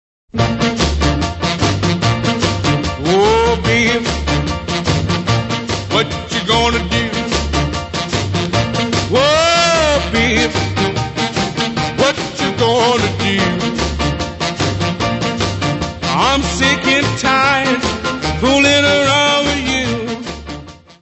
Music Category/Genre:  Jazz / Blues